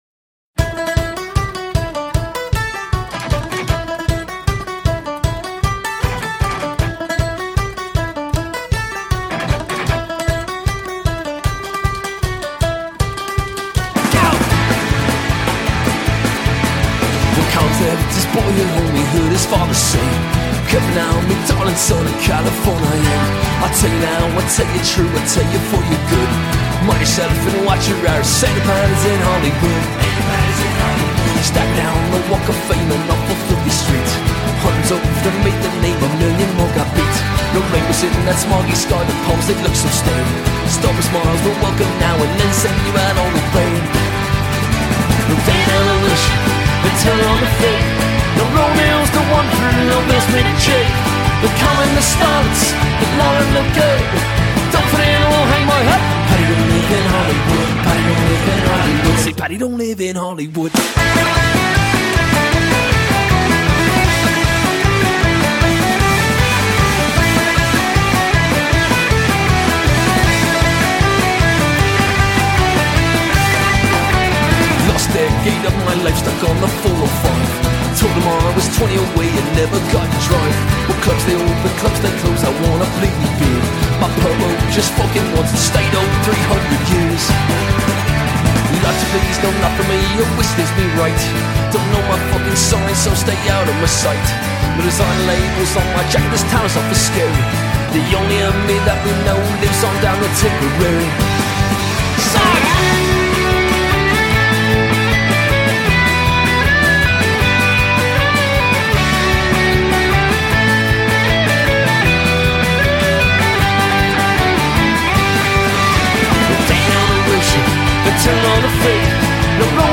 LA irish punk-rock and celtic band